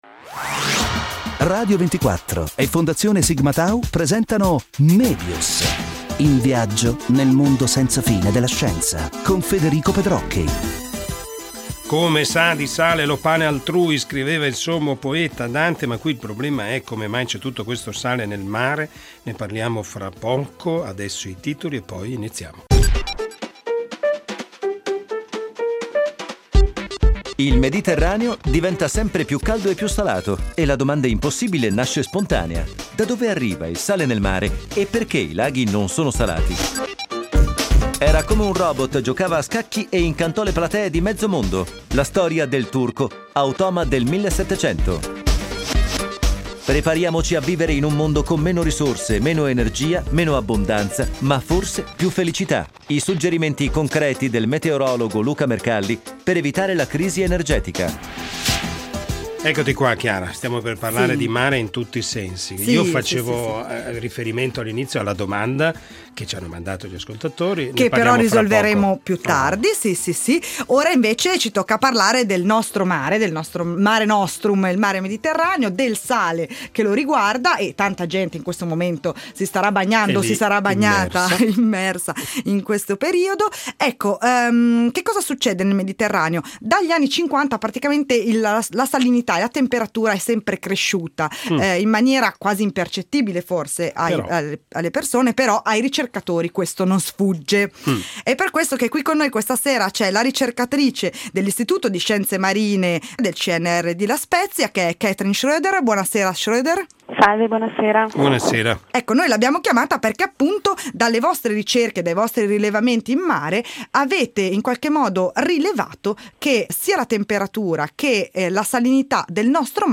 Scarica e ascolta lo stralcio dell'intervista in formato mp3